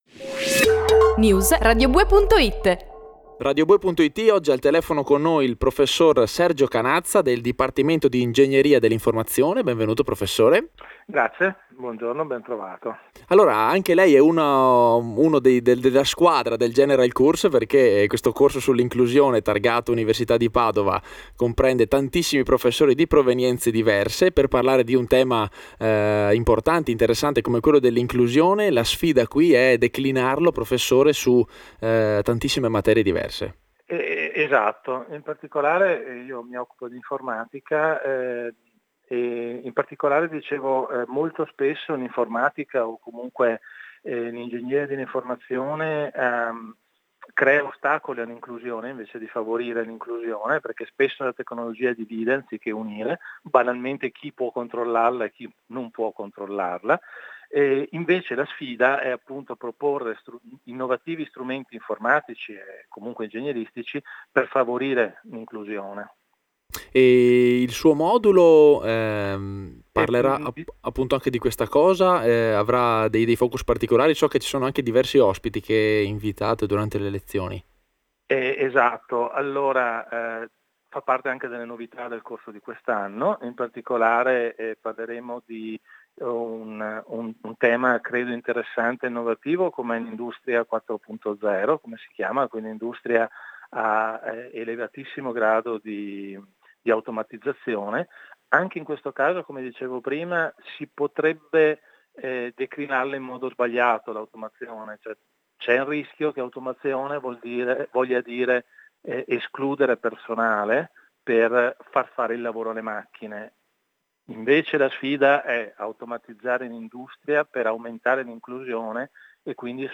intervista audio